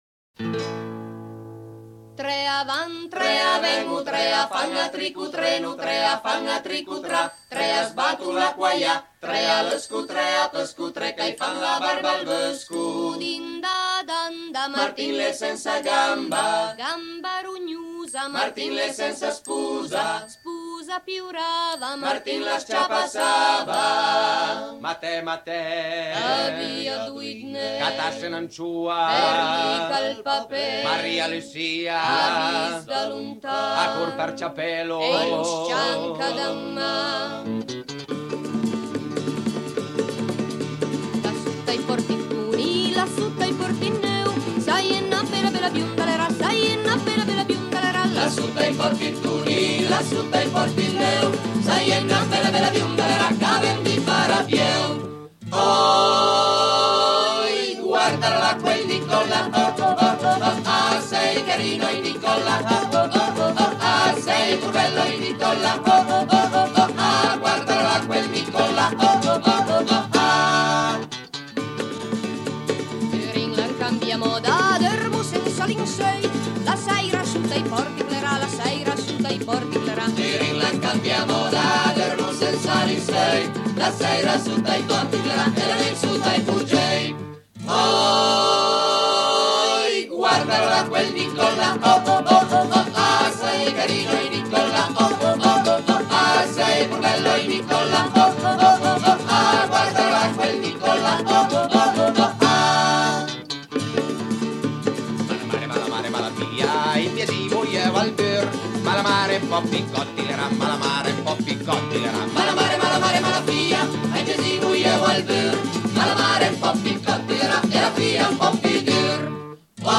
Musica popolare in Piemonte